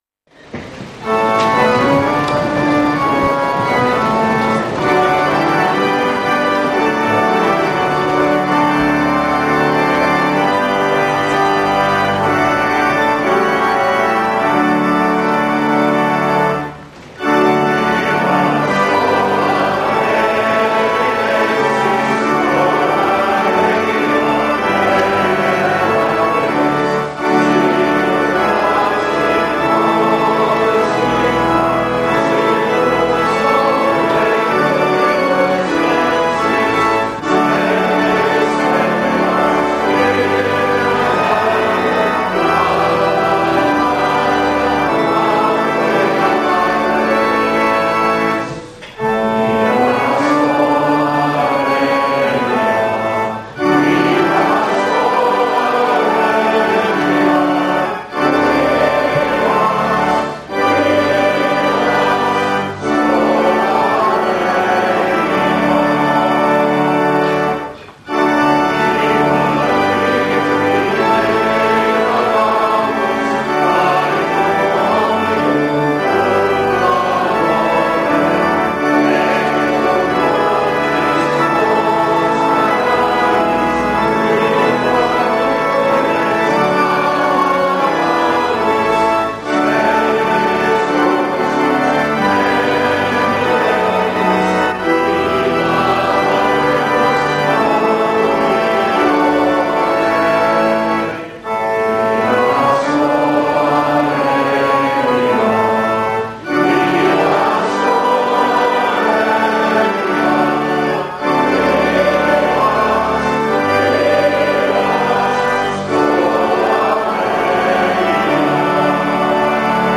vivas-schola-edinensis-prize-giving-version-with-organ.mp3